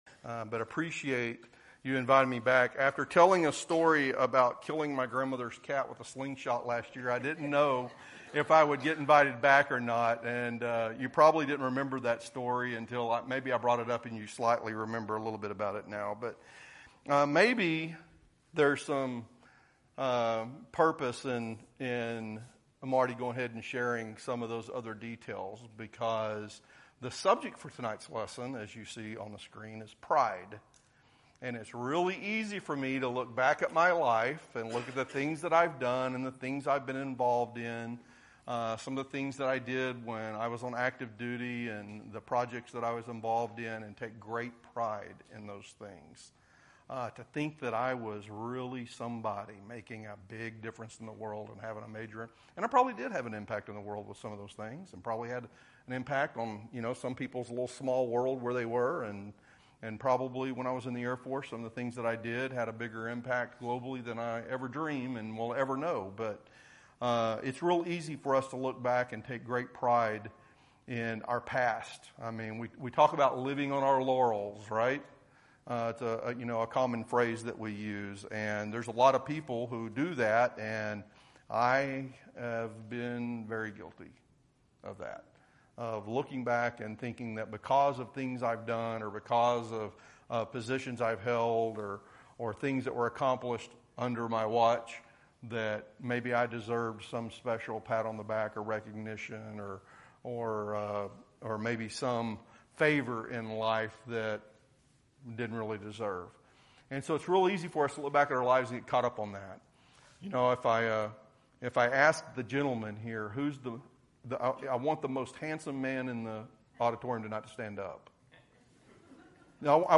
2025 Length 43:51 Pride isn’t always loud and arrogant—it often hides beneath our desire to be liked, our fear of being honest, or our obsession with our image. In this powerful message, we explore the rise and fall of Solomon and how even the wisest man on earth was brought low by pride.